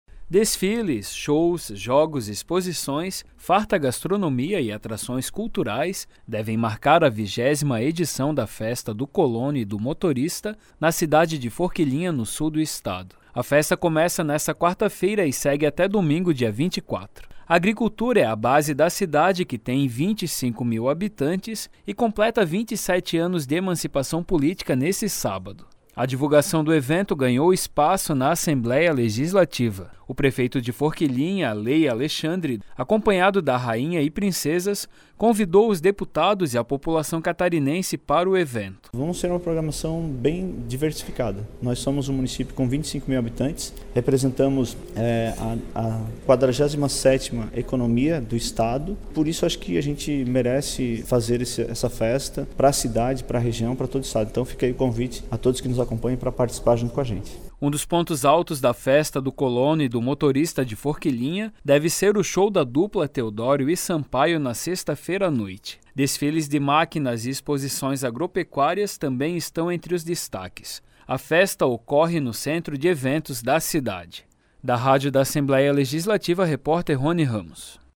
Entrevista com:
- Lei Alexandre, prefeito de Forquilhinha